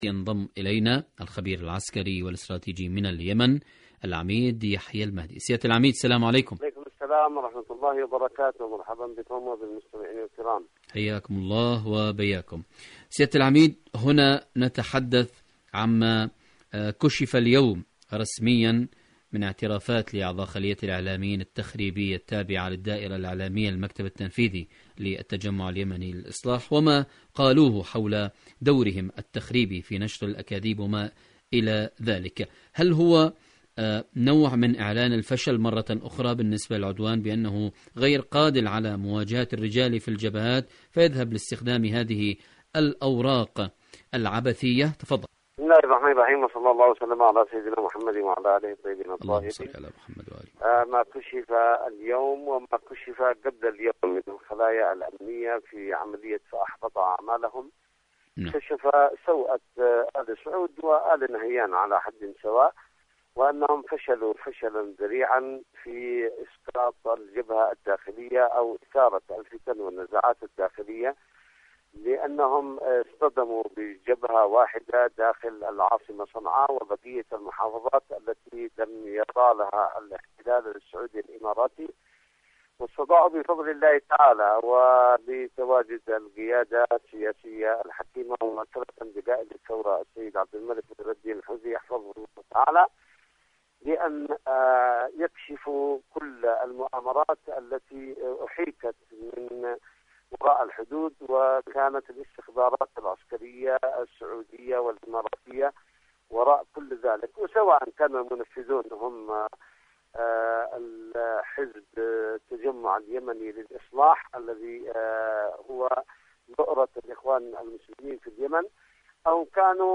مقابلات مقابلات إذاعية برامج إذاعة طهران العربية برنامج اليمن التصدي والتحدي اليمن العدوان السعودي على اليمن ورقة الجواسيس الفاشلة حركة أنصار الله شاركوا هذا الخبر مع أصدقائكم ذات صلة المرأة الحسينية وأدوارها..